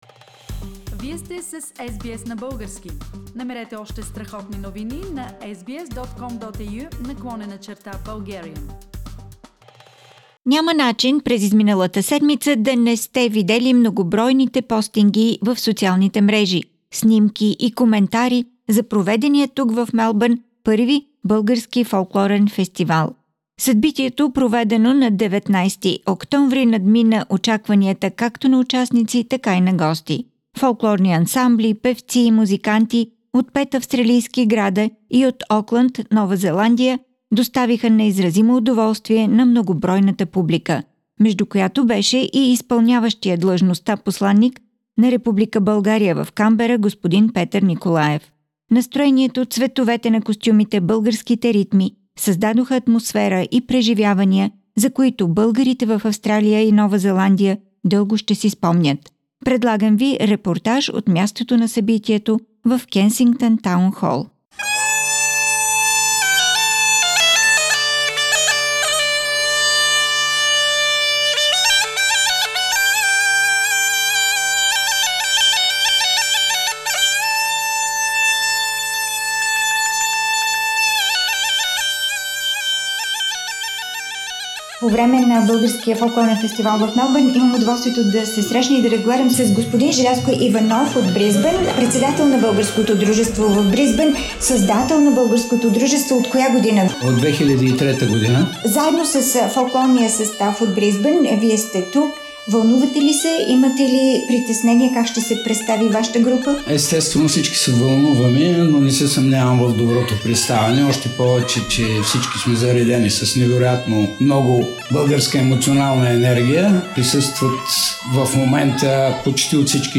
Bulgarians from Australia and New Zealand enjoyed the invigorating beauty of Bulgarian folk music and dances. Folk groups and individual performers from Sidney, Adelaide, Brisbane, Canberra, Melbourne and Auckland- New Zealand, performed in front of huge audience.